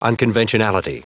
Prononciation du mot unconventionality en anglais (fichier audio)
Prononciation du mot : unconventionality